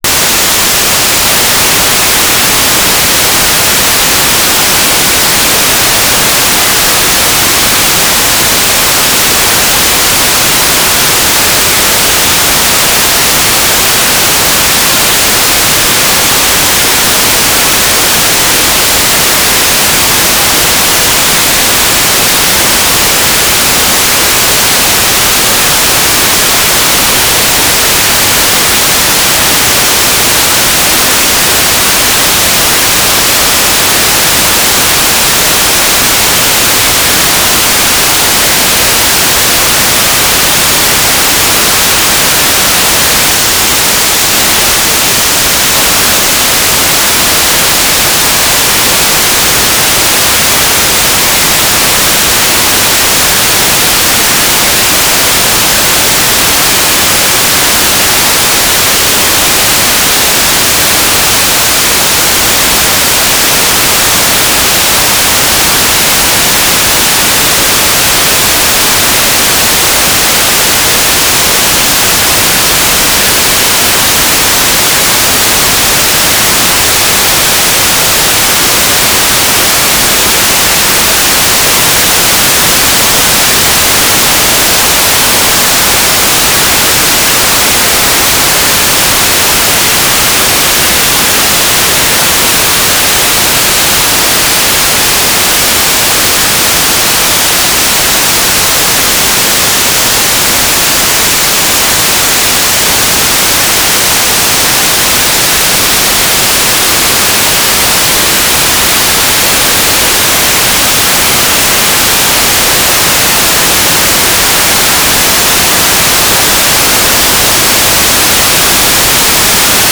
"transmitter_description": "S-band telemetry",
"transmitter_mode": "FMN",